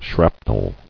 [shrap·nel]